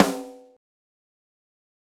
9 Echo
Mit einem Delay gibst Du deiner Snare Drum mehr Tiefe, wodurch sie größer wirkt. Stell das Feedback des Delays so ein, dass die Anschläge 1-3 Mal kurz hintereinander zu hören sind. Bei der Verzögerungszeit kannst Du dich an 1/8 oder kürzer halten, nur so viel, dass der Sound leicht “verschwimmt”.
tut_snare_drum_fett_klingen_lassen_09_verzoegern.mp3